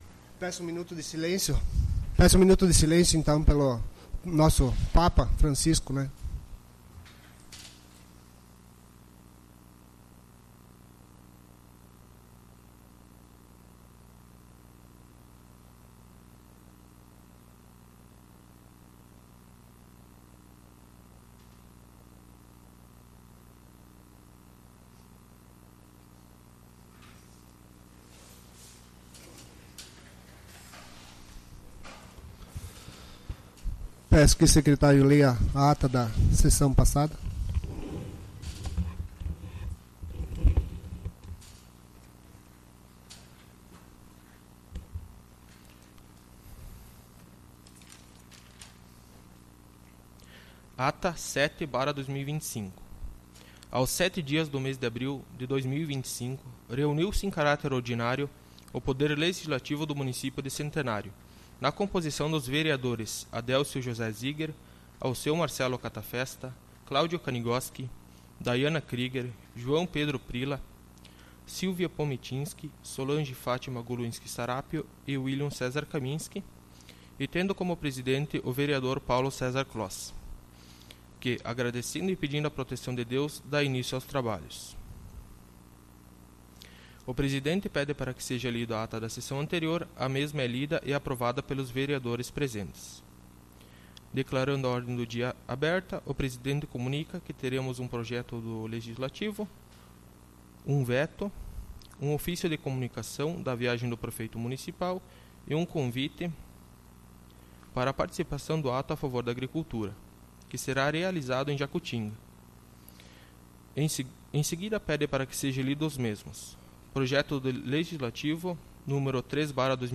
Sessão Ordinária do dia 22/04/2025